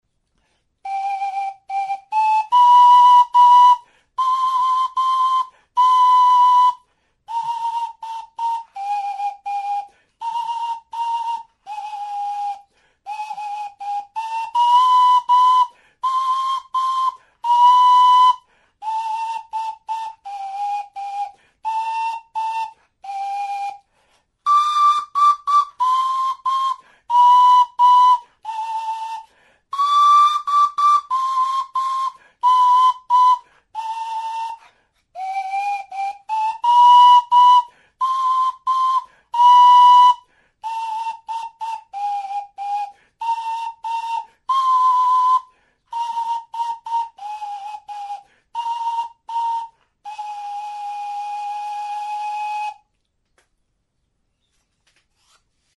Instrumentos de músicaTXULUBITA; SLIDE WHISTLE; pistoi flauta
Aerófonos -> Flautas -> Embolo
Grabado con este instrumento.
Zurezko tutua duen ahokodun flauta da. Doinuak osatzeko behekaldeko muturretik ziri bat sartua dauka, pistoi sistemazko flauta osatzeko.